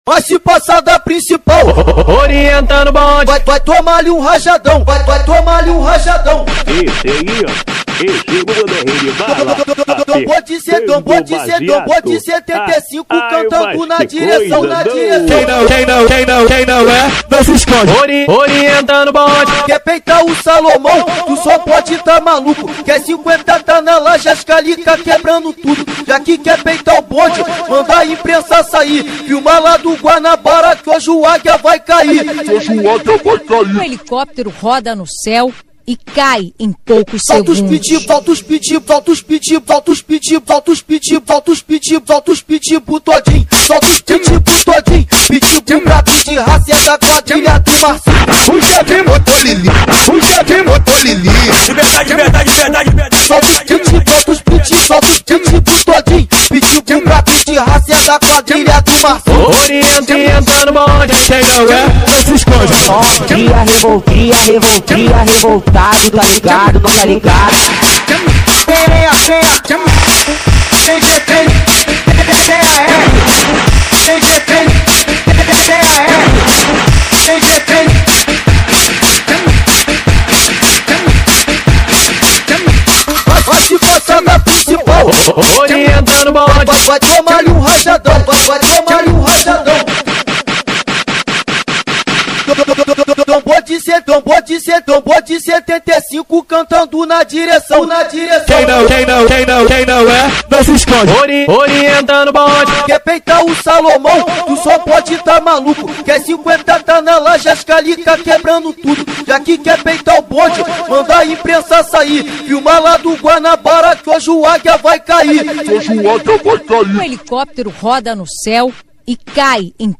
2025-02-05 23:16:13 Gênero: Funk Views